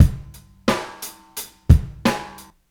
Brooklyn beat 1 88bpm.wav